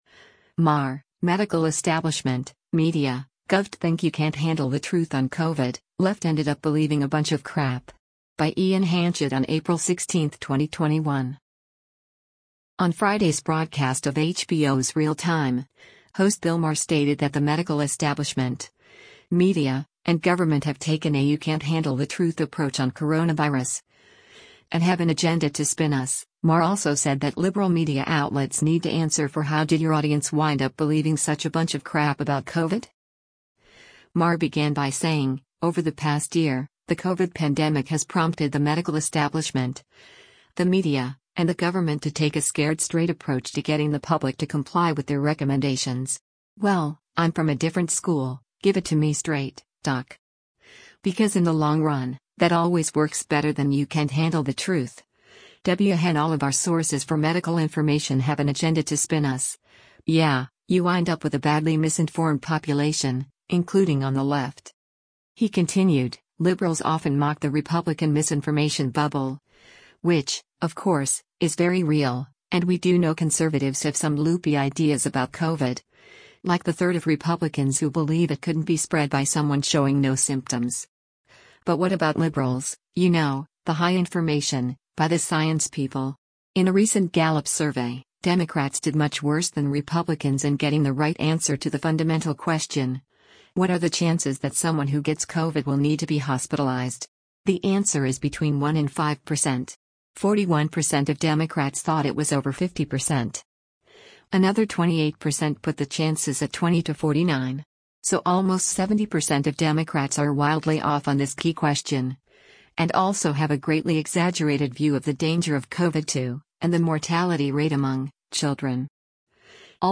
On Friday’s broadcast of HBO’s “Real Time,” host Bill Maher stated that the medical establishment, media, and government have taken a “you can’t handle the truth” approach on coronavirus, and “have an agenda to spin us,” Maher also said that liberal media outlets need to answer for “how did your audience wind up believing such a bunch of crap about COVID?”